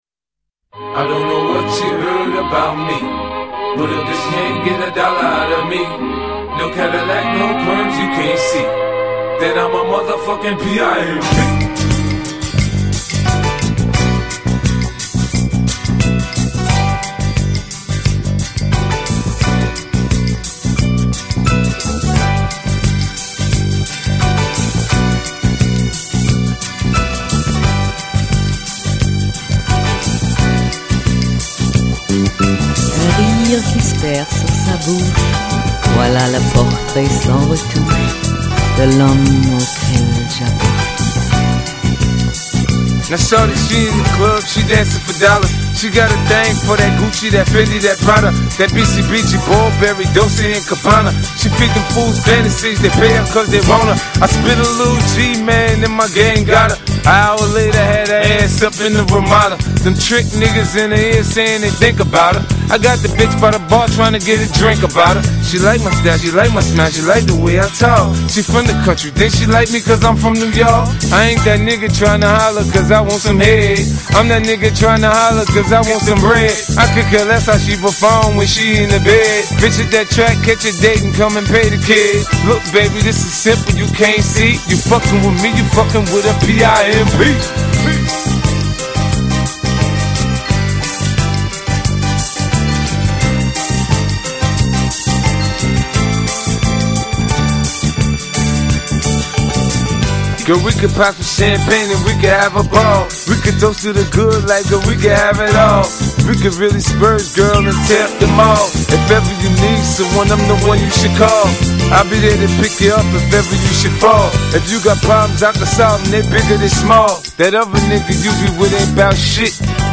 mash-up